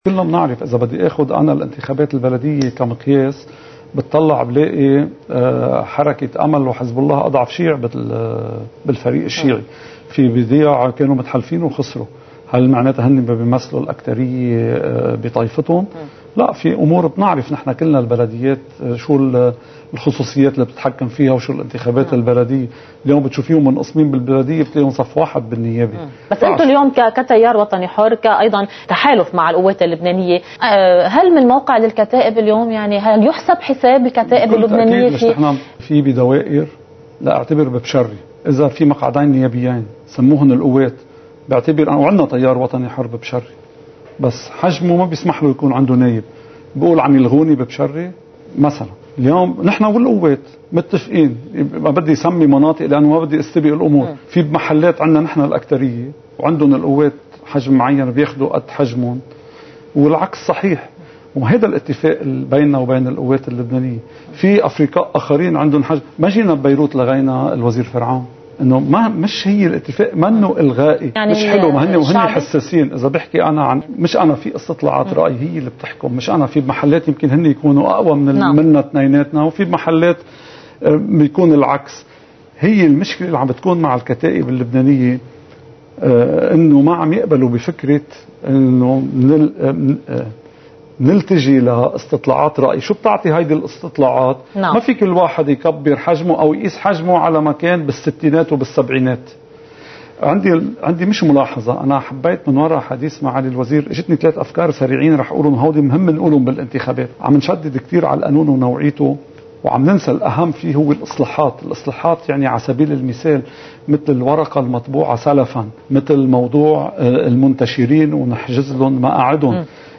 مقتطف من حديث النائب السابق سليم عون في حديث الى قناة الجديد